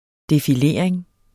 Udtale [ defiˈleˀɐ̯eŋ ]